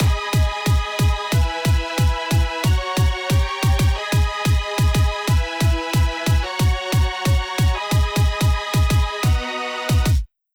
Hardcore
※切り替わりのタイミングが分かりにくかったのでキック付きです。
これはただの4-5-6-1進行です。